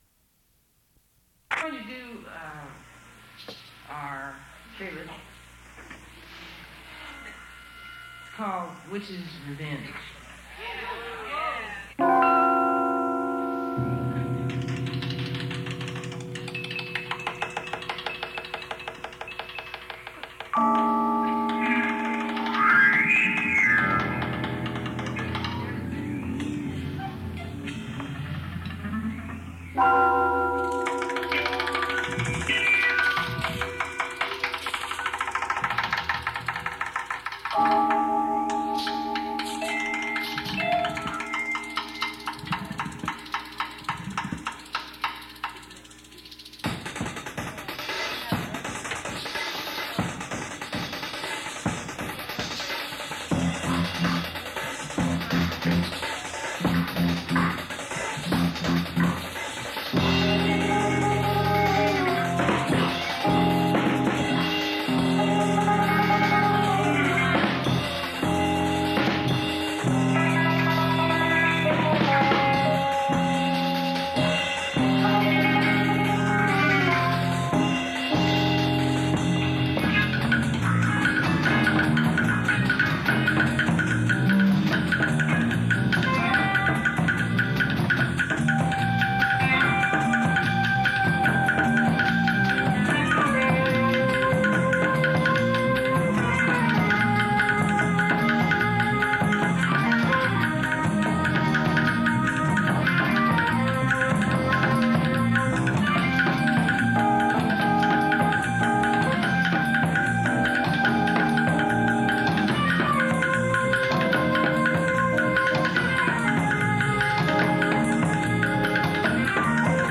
Radio talk shows
Audio cassette